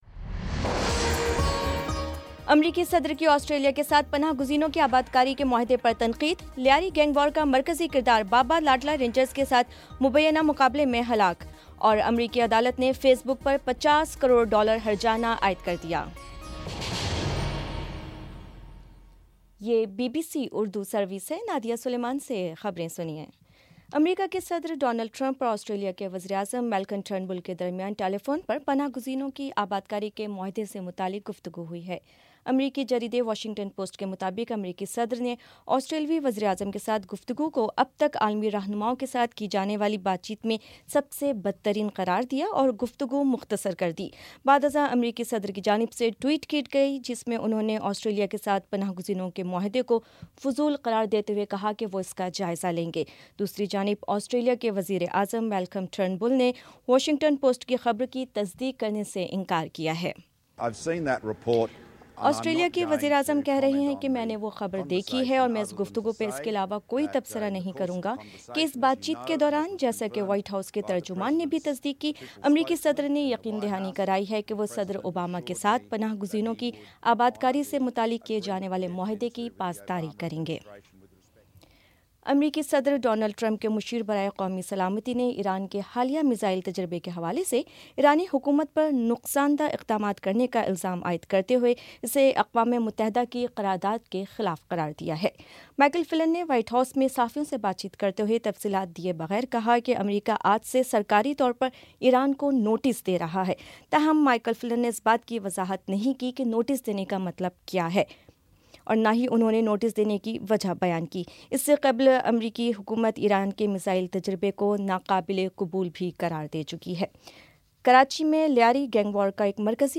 فروری 02 : شام پانچ بجے کا نیوز بُلیٹن